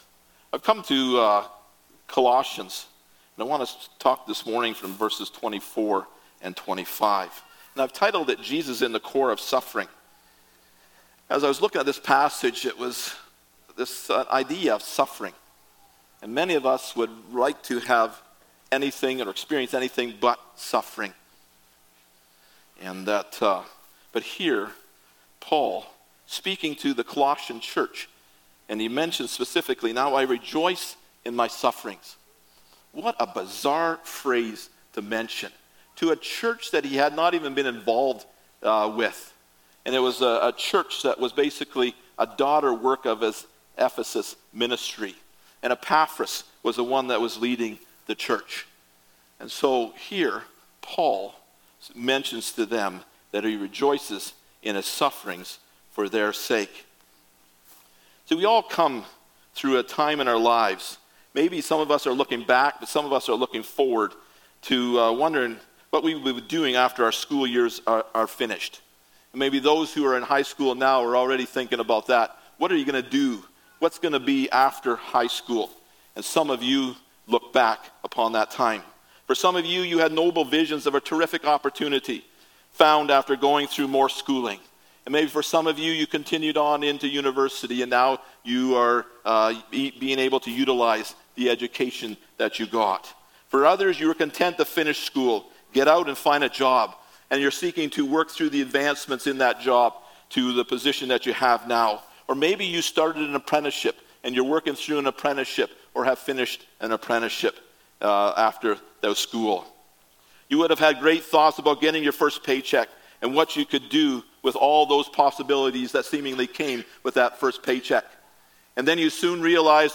Jesus at the Core Passage: Colossians 1:24-25 Service Type: Sunday Morning « Jesus